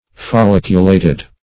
Fol*lic"u*la`ted